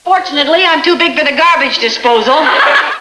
(65 kb) - Lucy saying she's to big for the garbage disposal.